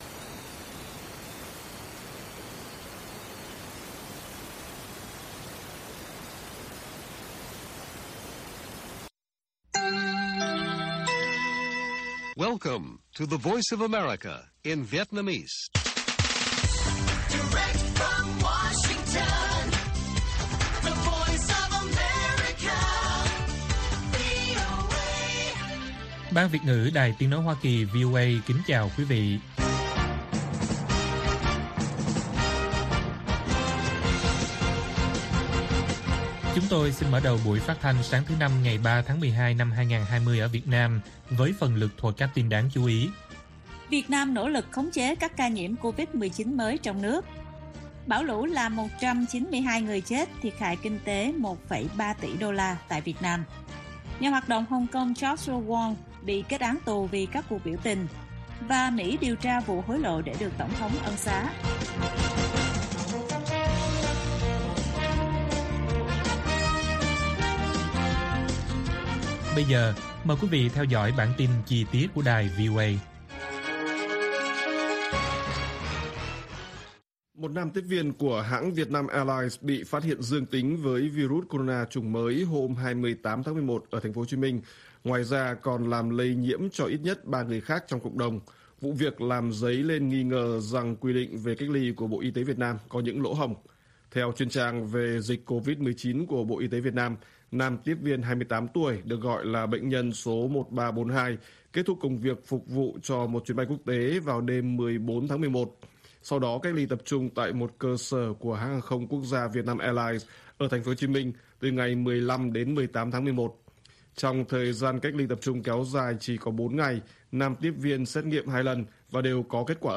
Bản tin VOA ngày 3/12/2020